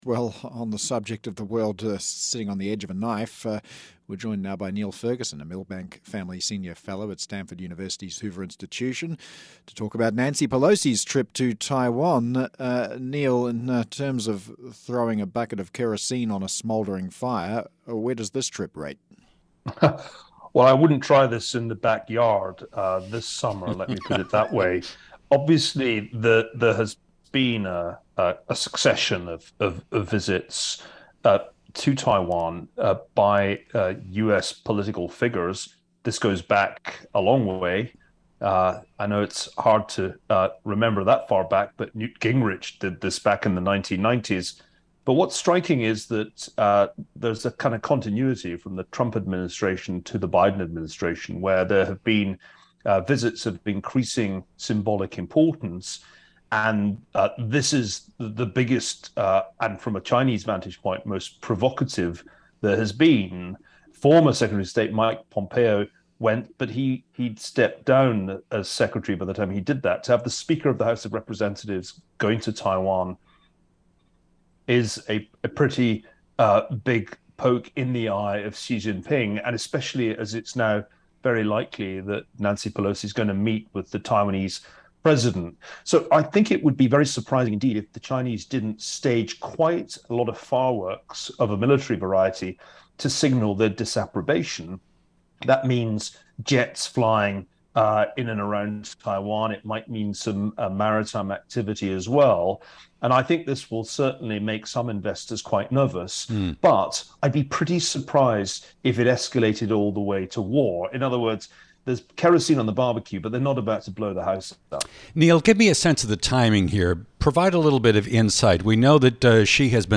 Niall Ferguson, Milbank Family Senior Fellow at the Hoover Institution, gives his take on Nancy Pelosi's trip to Taiwan.